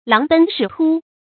láng bēn shǐ tū
狼奔豕突发音